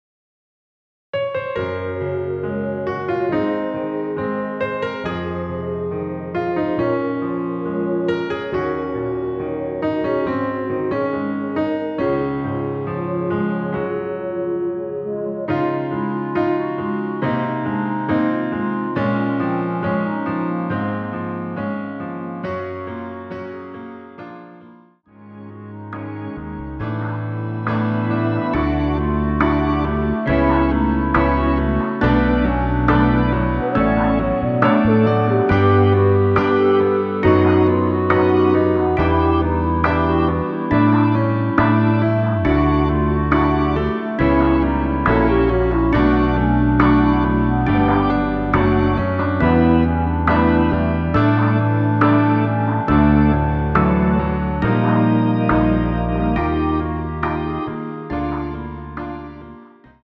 원키에서(-1)내린 멜로디 포함된 MR입니다.
Db
노래방에서 노래를 부르실때 노래 부분에 가이드 멜로디가 따라 나와서
앞부분30초, 뒷부분30초씩 편집해서 올려 드리고 있습니다.
중간에 음이 끈어지고 다시 나오는 이유는